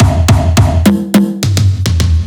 105 BPM Beat Loops Download